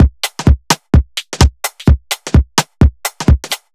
Index of /musicradar/uk-garage-samples/128bpm Lines n Loops/Beats